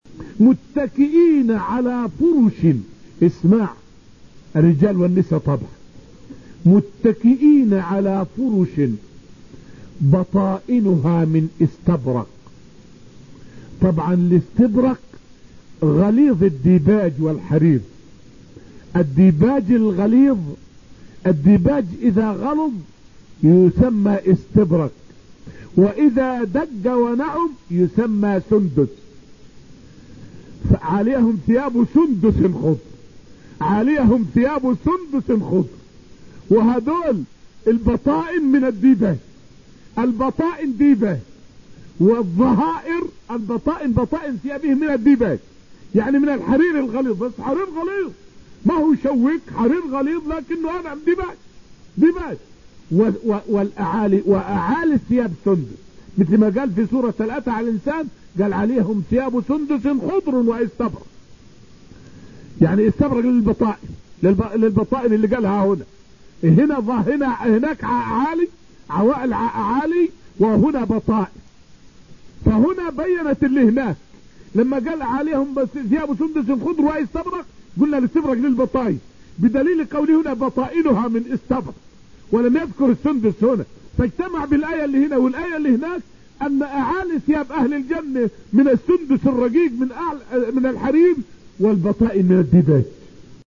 فائدة من الدرس الثاني من دروس تفسير سورة الرحمن والتي ألقيت في المسجد النبوي الشريف حول مذاهب وأباطيل الناس في صفة كلام الله تعالى وأسباب نشوئها.